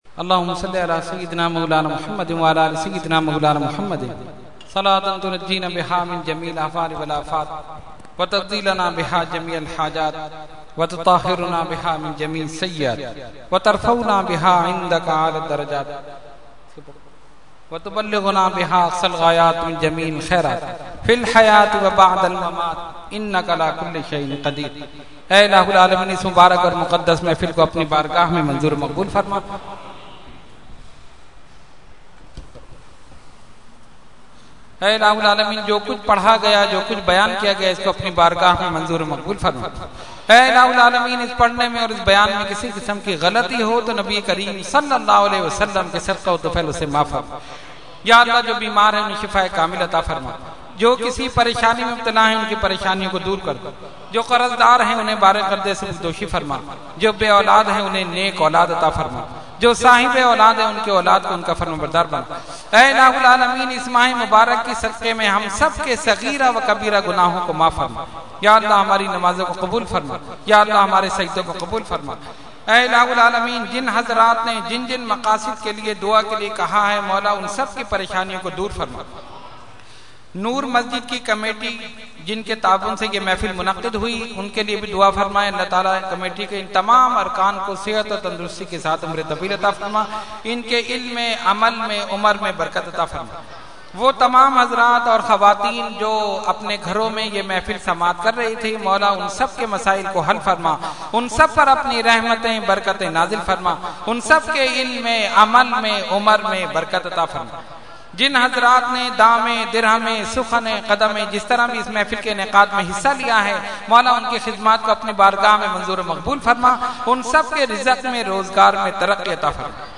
Dua – Mehfil Ramzan Noor Masjid 5 August 2012 – Dargah Alia Ashrafia Karachi Pakistan